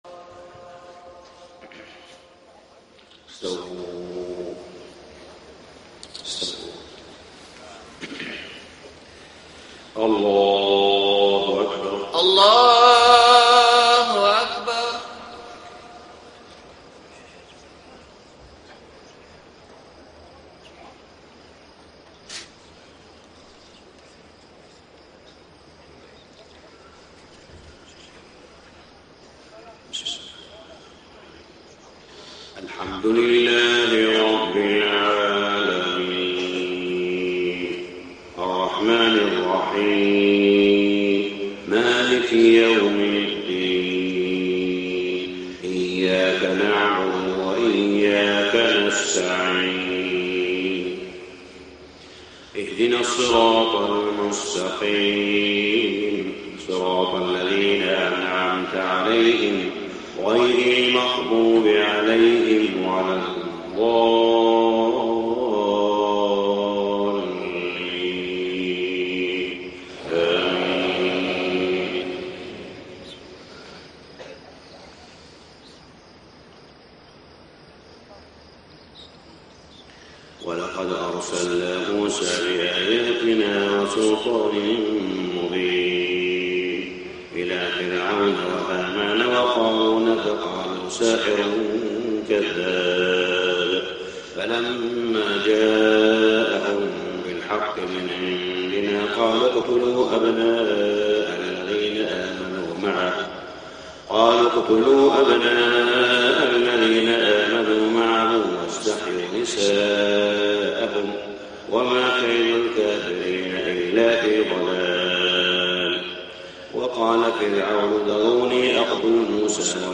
صلاة الفجر 7-5-1434 من سورة غافر > 1434 🕋 > الفروض - تلاوات الحرمين